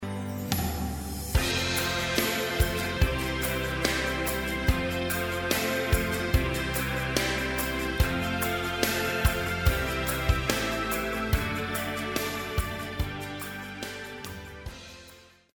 流行
套鼓(架子鼓)
乐团
演奏曲
独奏与伴奏
有主奏
有节拍器